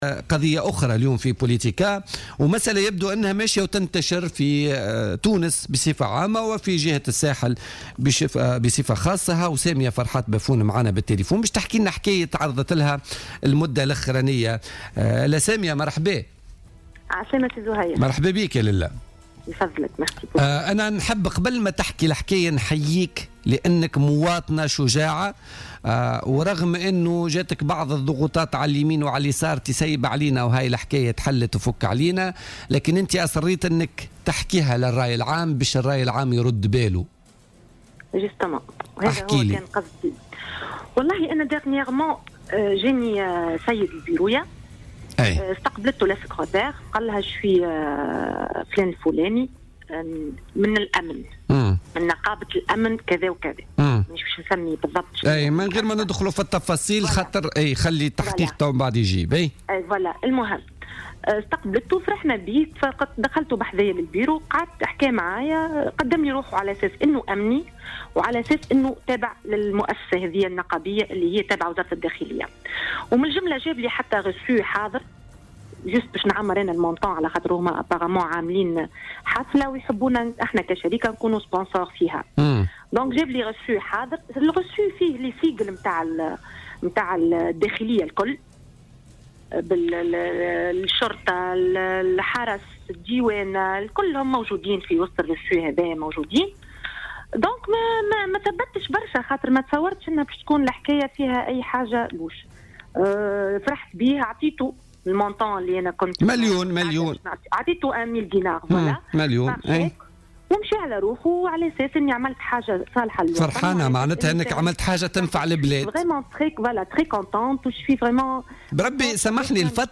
أكدت مديرة إحدى الشركات بسوسة في مداخلة لها اليوم،الثلاثاء في برنامج "بوليتيكا" تعرضها إلى عملية احتيال.